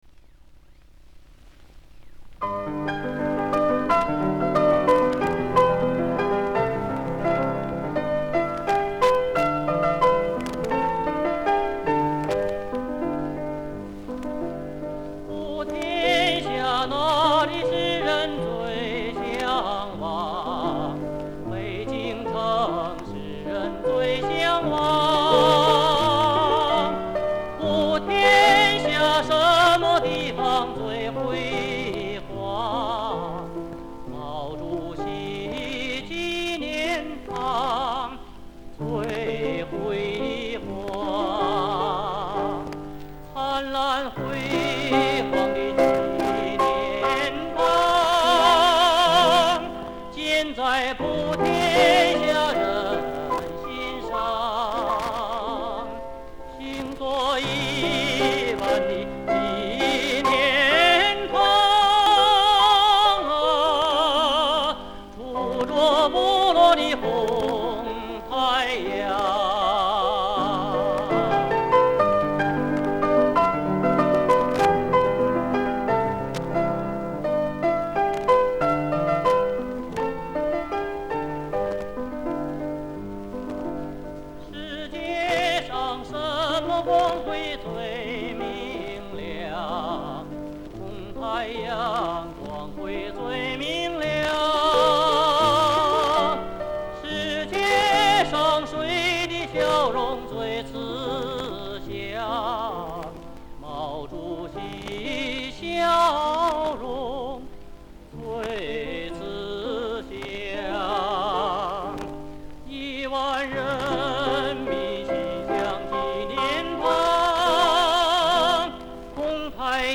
钢琴伴奏